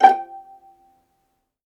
VIOLINP .6-R.wav